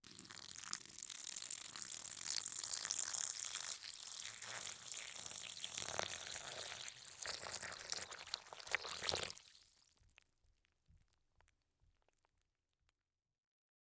Звук выливания молока из бидона